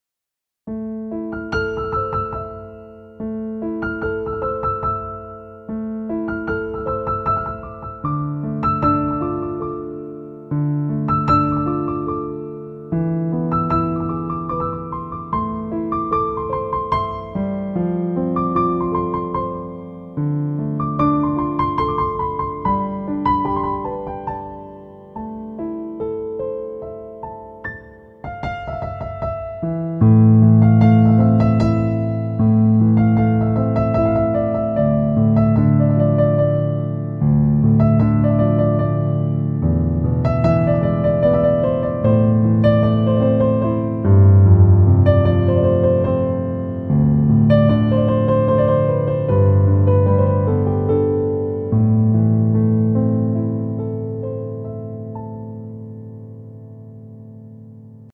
صدای زنگ ملایم